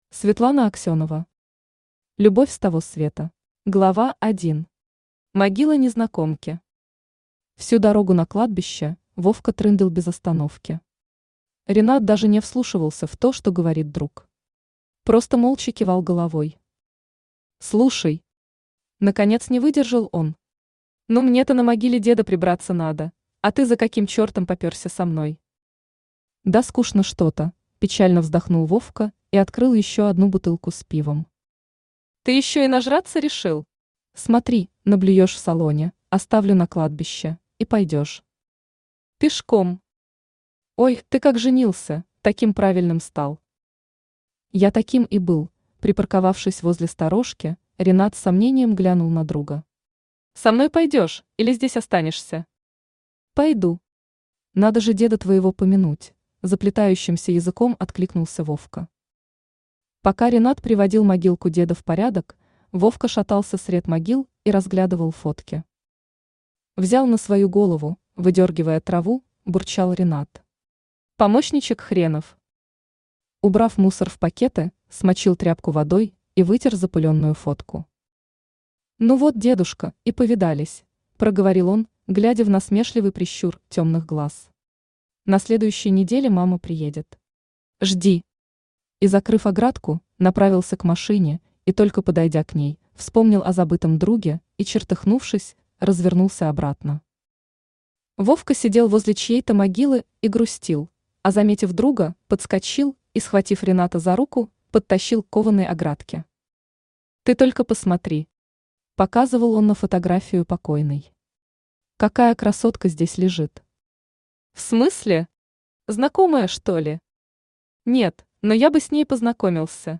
Aудиокнига Любовь с того света Автор Светлана Алексеевна Аксенова Читает аудиокнигу Авточтец ЛитРес.